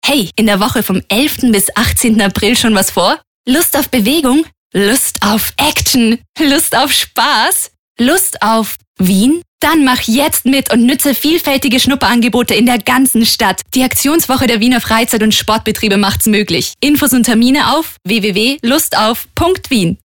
Lektor
austriacki
profesjonalny lektor filmowy dostępny od ręki.